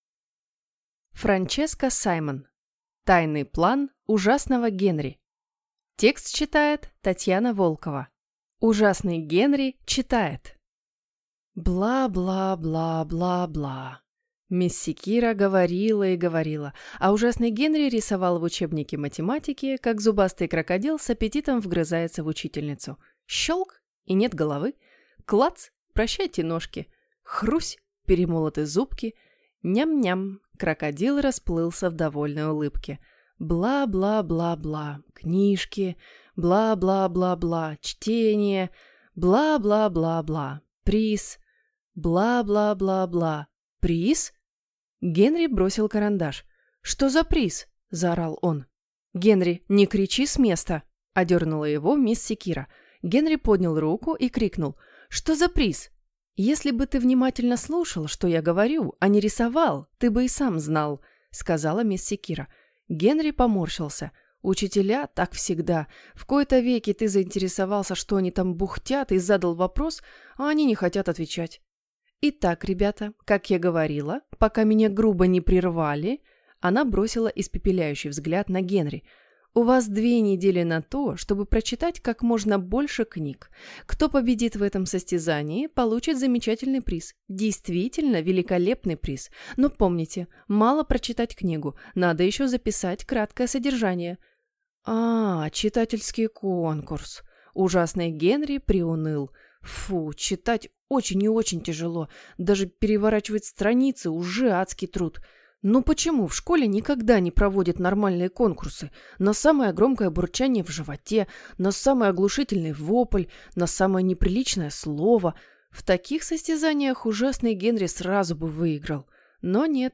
Аудиокнига Тайный план Ужасного Генри | Библиотека аудиокниг